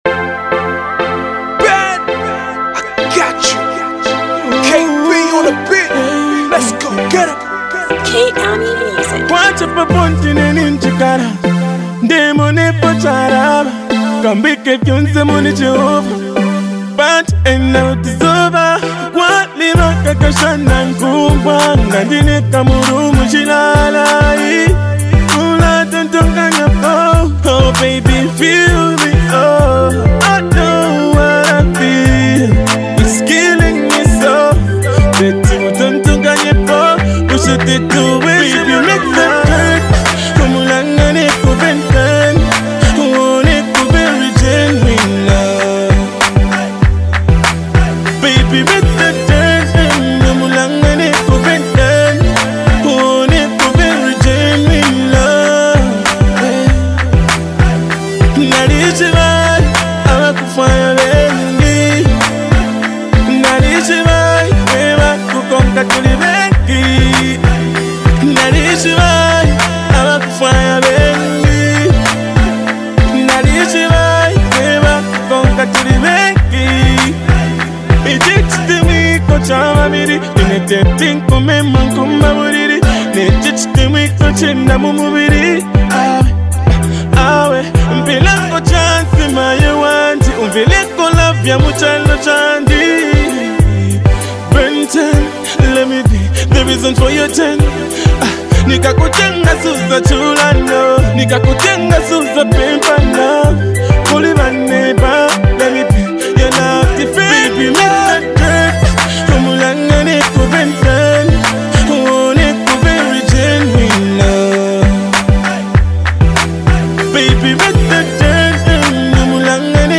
single
Beautiful RnB sounds